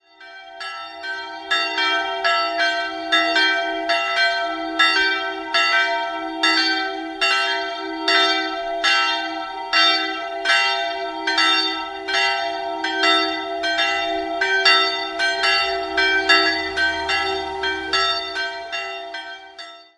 Am Ortsrand steht die kleine Michaelskirche, die im 17. Jahrhundert errichtet wurde. 2-stimmiges Kleine-Terz-Geläute: e''-g'' Die größere Glocke wurde im Jahr 1931 von Johann Hahn und Sohn in Landshut/Reichenhall gegossen, die kleinere dürfte aus der Zeit um 1500 aus Nürnberg stammen.